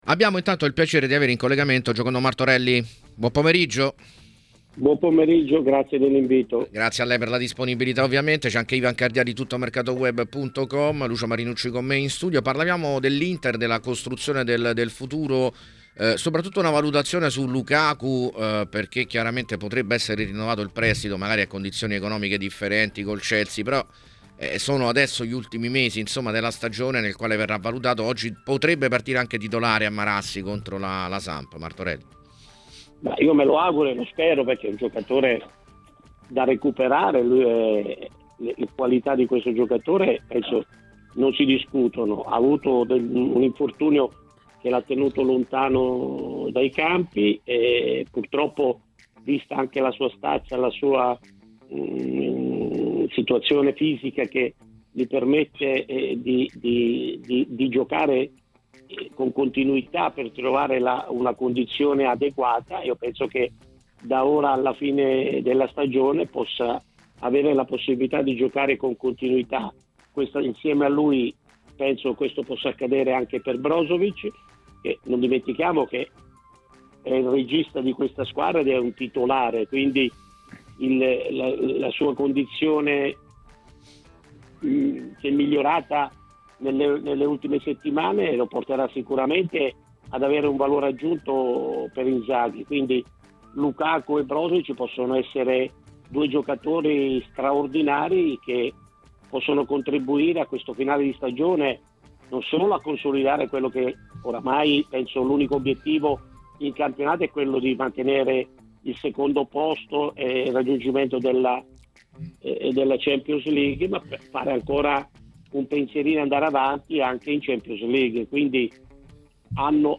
Intervistato da TMW Radio